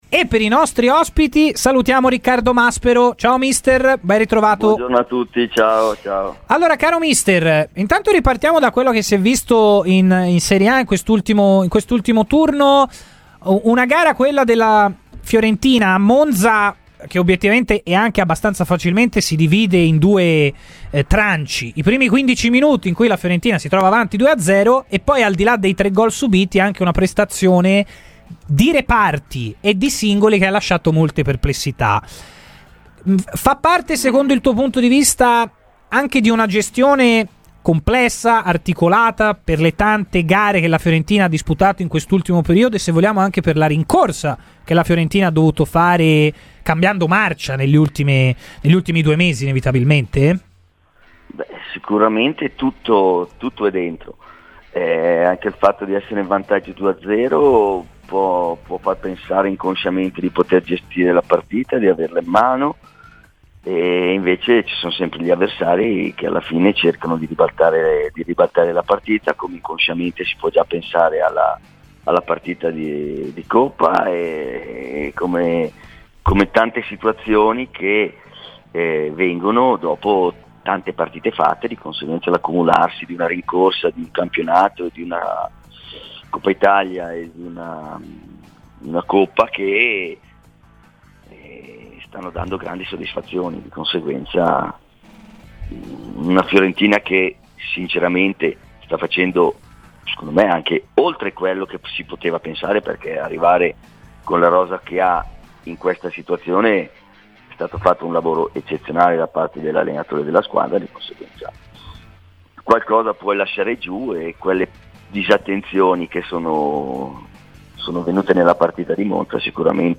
ha parlato ai microfoni di Radio FirenzeViola .